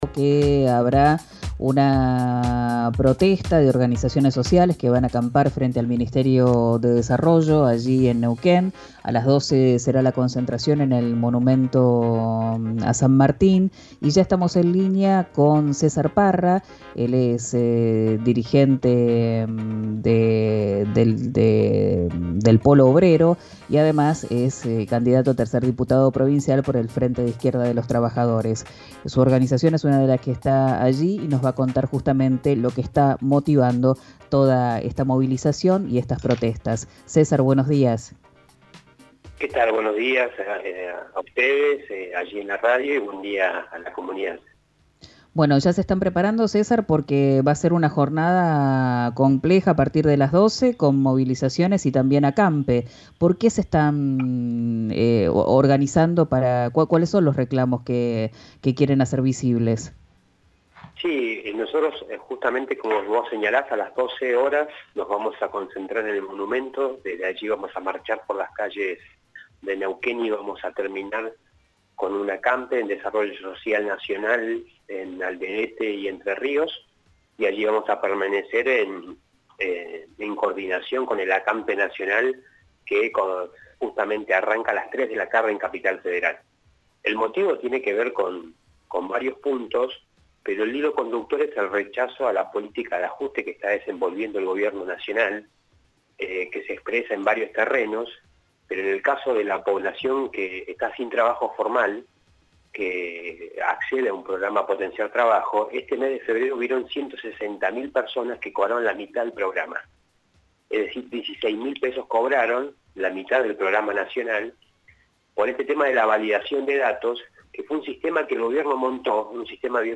en diálogo con «Quien dijo verano» por RÍO NEGRO RADIO: